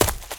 STEPS Leaves, Run 24.wav